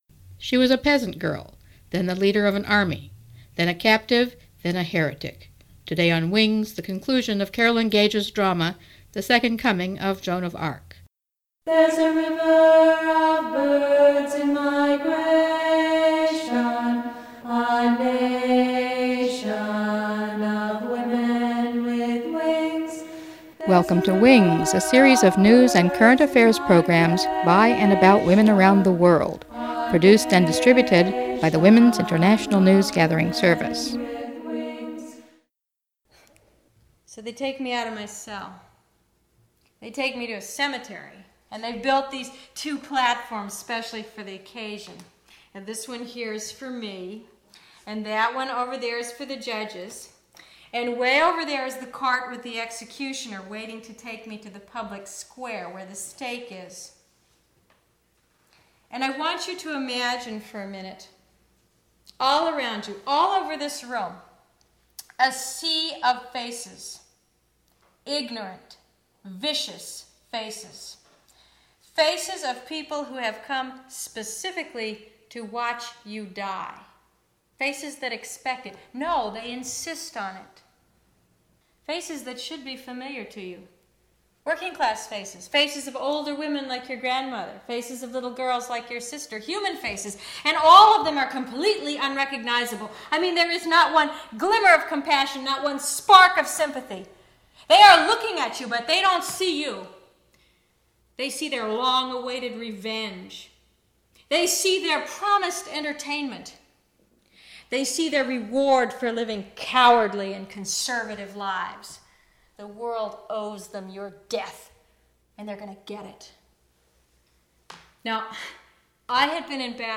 WINGS #09-16, Part 3 of The Second Coming of Joan of Arc - a 3-part radio drama